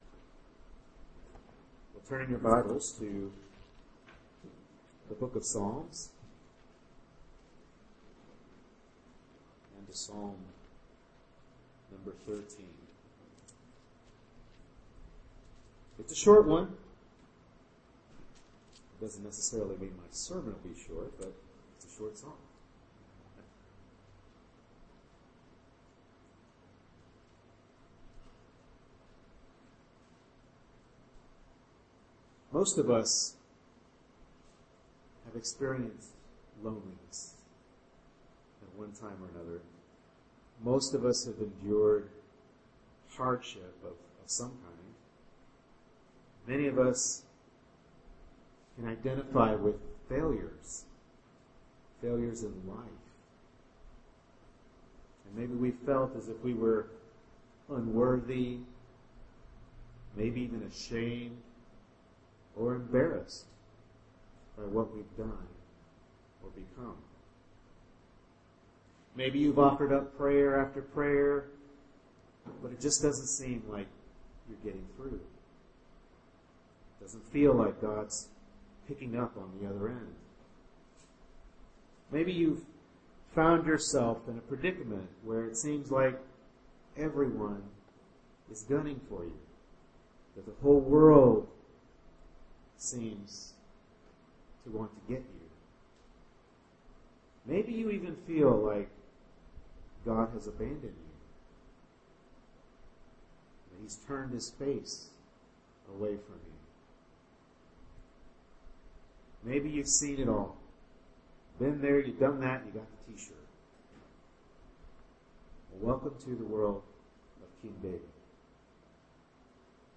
Psalm 13 Service Type: Morning Worship Service Bible Text